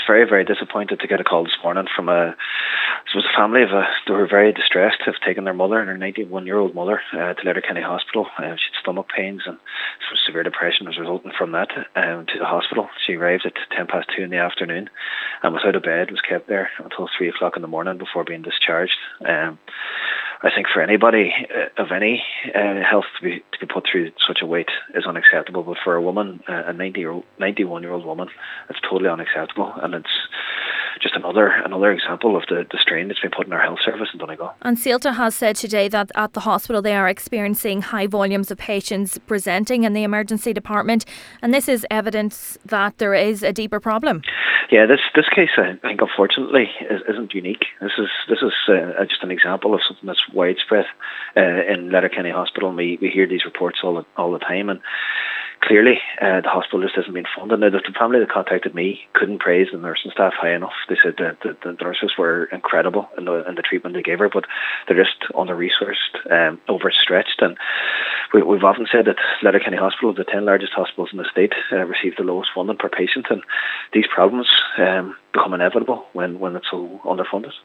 Councillor Jack Murray says this is just one example of the widespread crisis existing at the hospital: